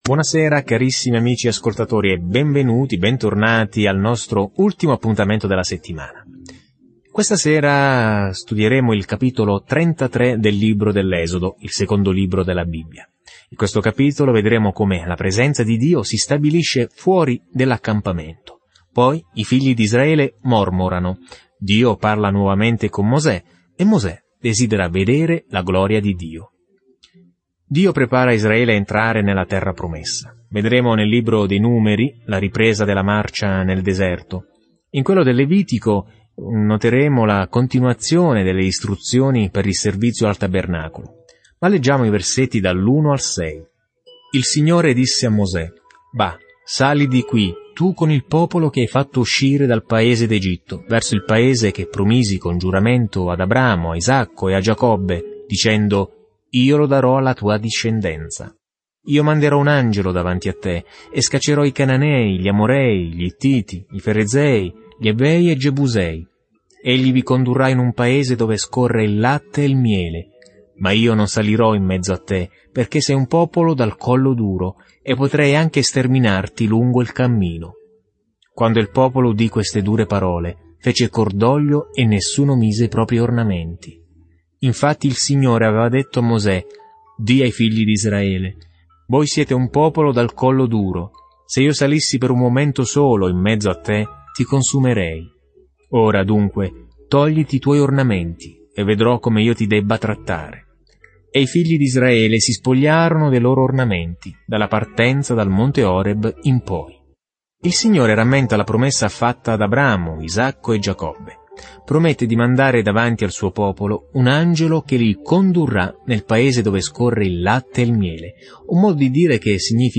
Scrittura Esodo 33 Esodo 34:1-8 Giorno 39 Inizia questo Piano Giorno 41 Riguardo questo Piano L'Esodo ripercorre la fuga di Israele dalla schiavitù in Egitto e descrive tutto ciò che accadde lungo il percorso. Viaggia ogni giorno attraverso l'Esodo mentre ascolti lo studio audio e leggi versetti selezionati della parola di Dio.